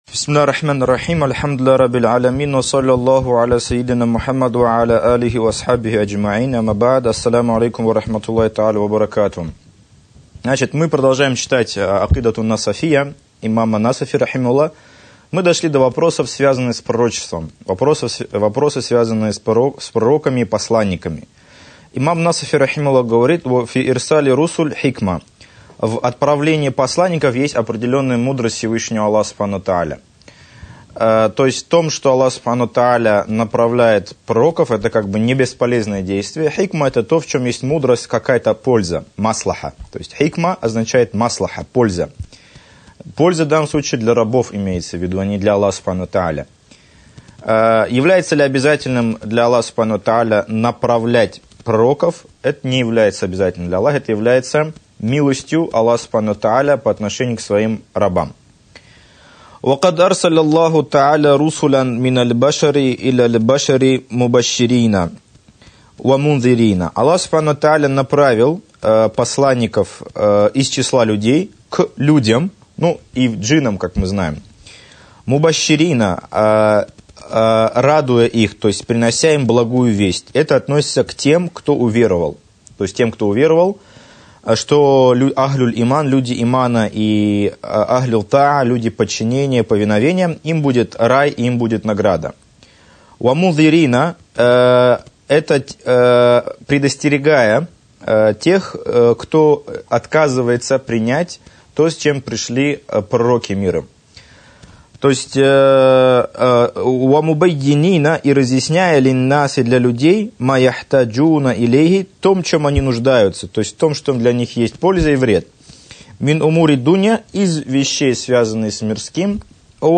Уроки по акыде: Акыда ан-Насафия